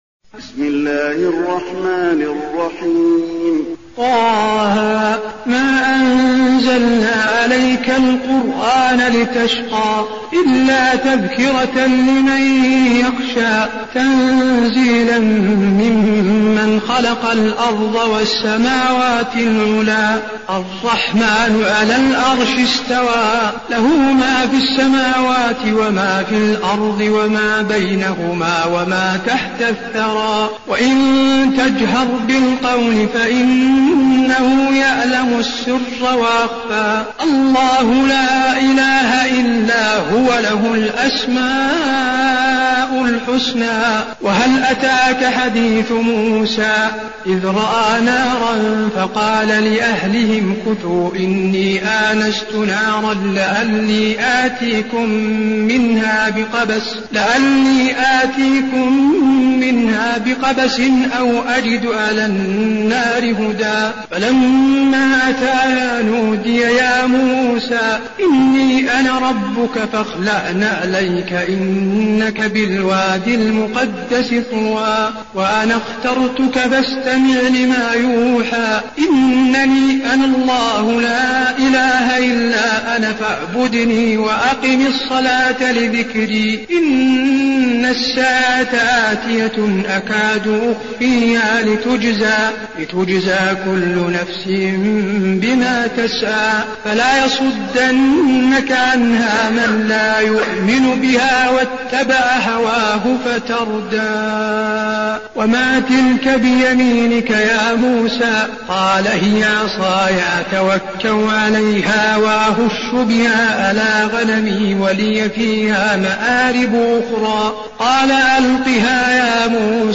المكان: المسجد النبوي طه